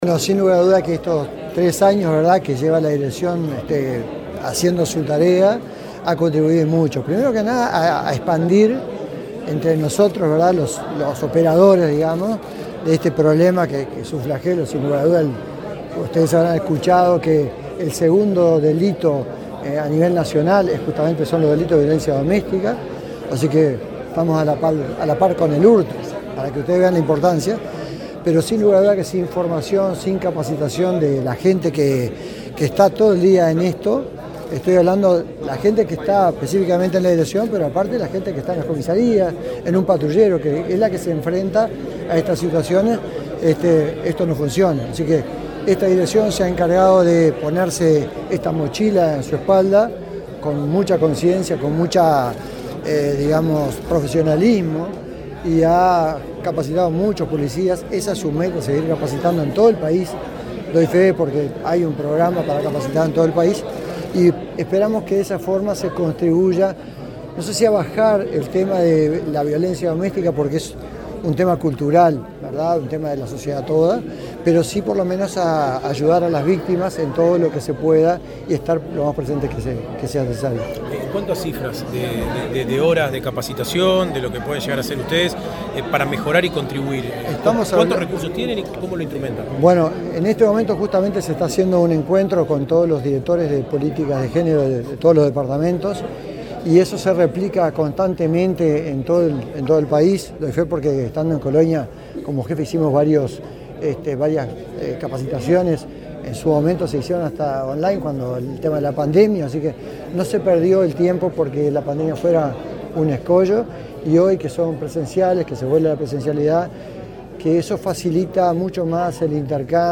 Declaraciones del subdirector de la Policía Nacional, Jhonny Diego Sosa
Declaraciones del subdirector de la Policía Nacional, Jhonny Diego Sosa 27/07/2023 Compartir Facebook X Copiar enlace WhatsApp LinkedIn El subdirector de la Policía Nacional, Jhonny Diego Sosa, dialogó con la prensa luego de participar en el acto por el aniversario de la Dirección General de Políticas de Género, del Ministerio del Interior.